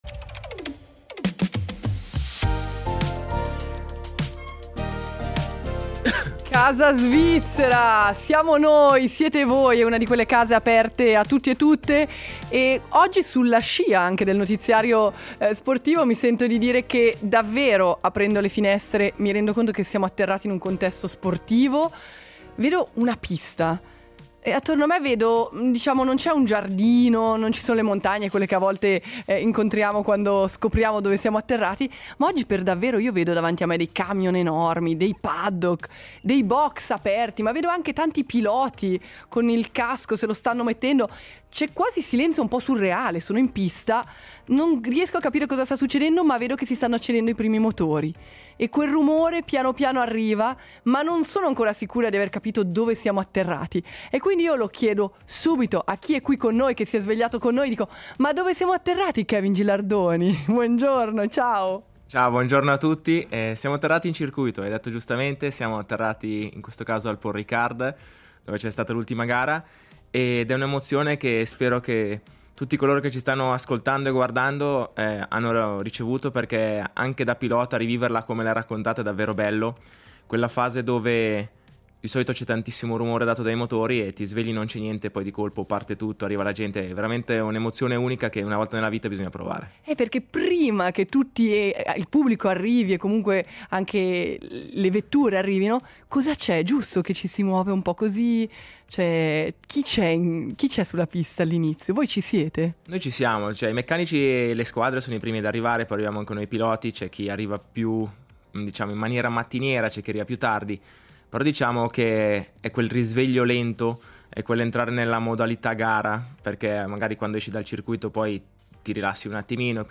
Abbiamo aperto la finestra e ci siamo trovati un paddock: camion enormi, motorhome, box aperti, tutto ancora sospeso nel silenzio del mattino.
Poi qualcosa è cambiato: si sono accesi i primi motori, è arrivato il rumore, quello vero, sono entrati i meccanici, i piloti, lo speaker ha iniziato a parlare… e quel silenzio è diventato uno stadio a cielo aperto.